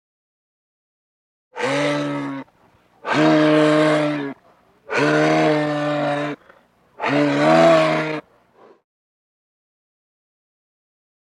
Moose; Calls And Raspy Honks, Close Perspective.